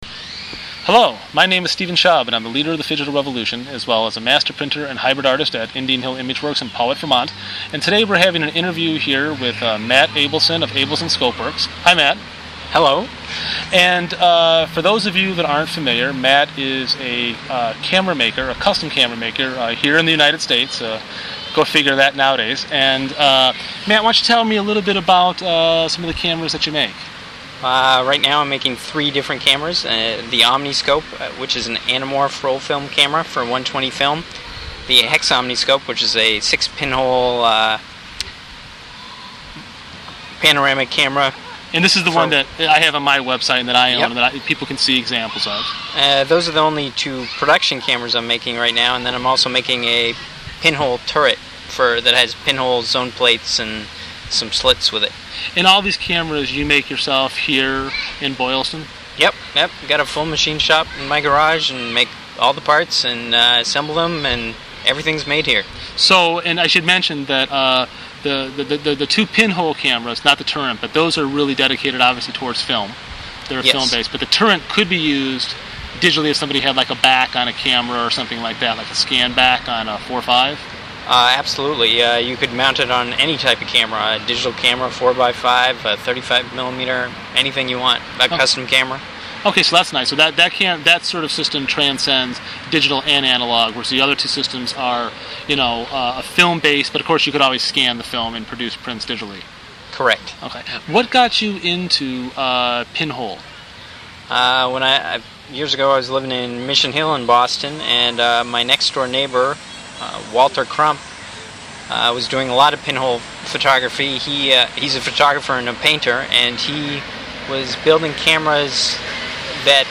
S.K.Grimes Interview Many of the camera systems I have used over the last 2+ decades have been either custom made or modifications to older systems to “fit” newer capture devices.